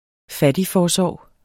Udtale [ ˈfadiˌfɒːˌsɒˀw ]